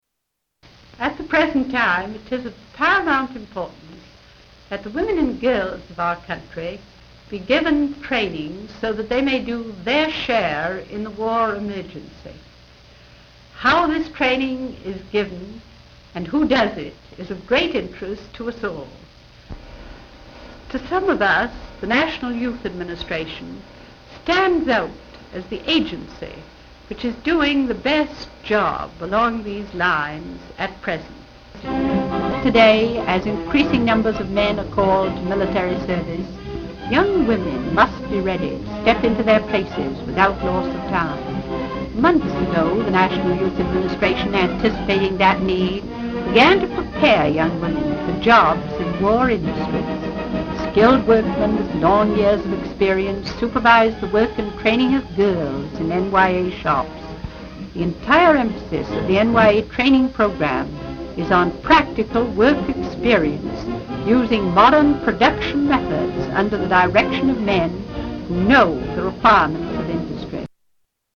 Tags: Historical Anna Eleanor Roosevelt Audio Eleanor Roosevelt Eleanor Roosevelt Speeches Political